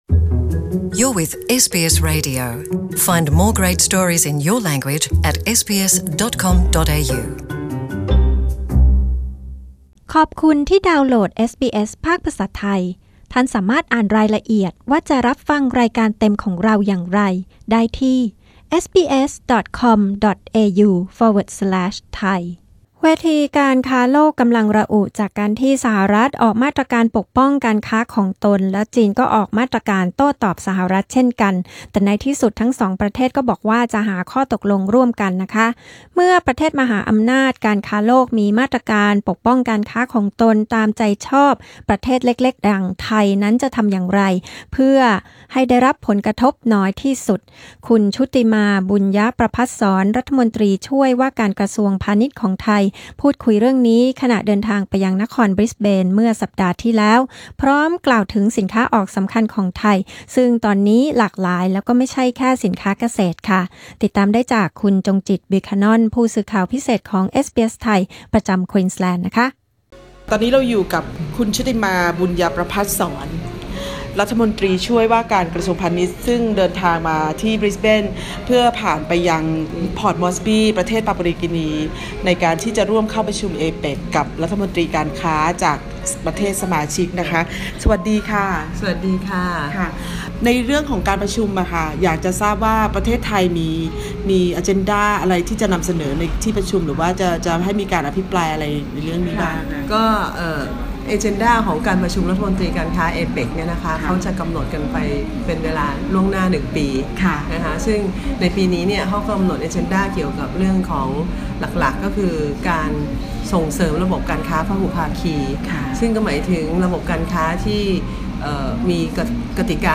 เมื่อมหาอำนาจทางการค้าโลก มีมาตรการปกป้องการค้าของตนตามใจชอบ ไทยจะทำอย่างไรให้ได้รับผลกระทบน้อยที่สุด คุณชุติมา บุณยประภัศร รัฐมนตรีช่วยว่าการกระทรวงพาณิชย์ พูดคุยเรื่องนี้กับเอสบีเอส ไทย